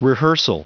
Prononciation du mot rehearsal en anglais (fichier audio)
Prononciation du mot : rehearsal